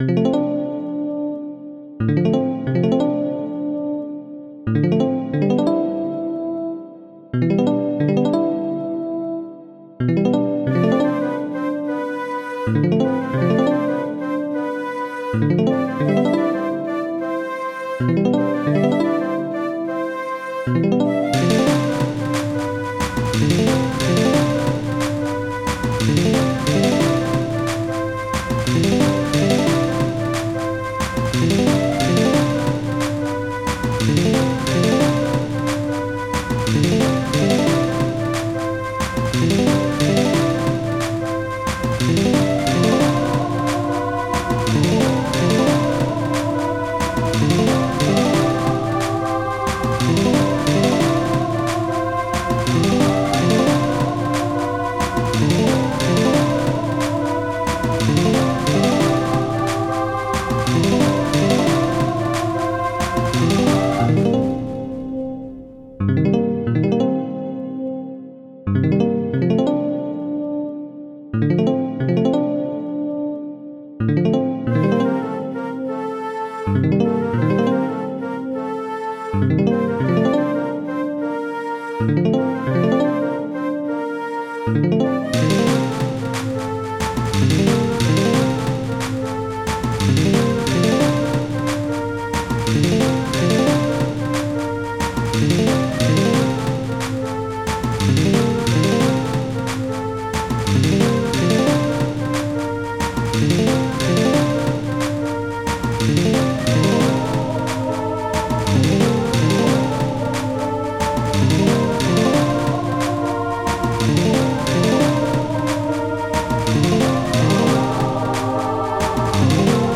This song goes from C Major to A# Major sometimes.